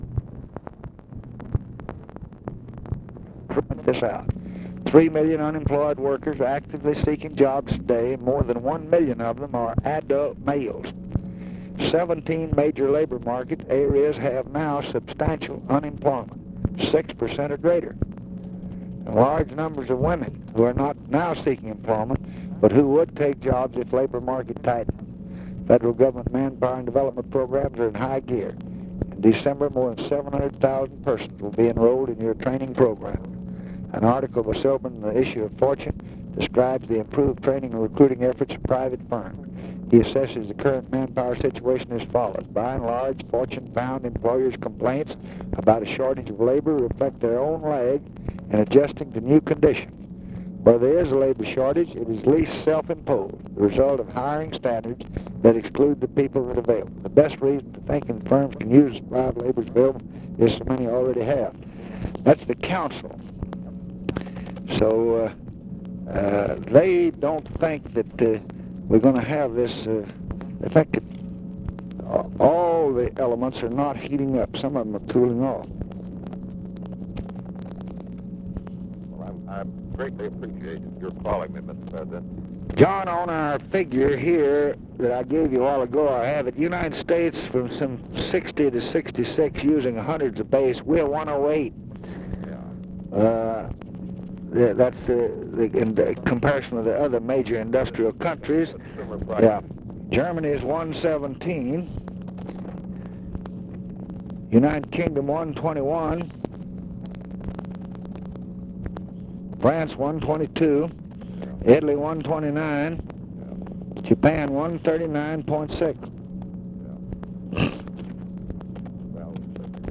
Conversation with JOHN COWLES, June 8, 1966
Secret White House Tapes